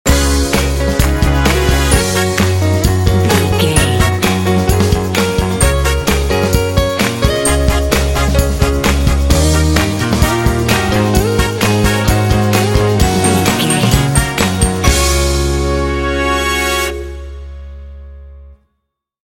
Ionian/Major
energetic
playful
lively
cheerful/happy
piano
trumpet
electric guitar
brass
percussion
bass guitar
drums
rock
classic rock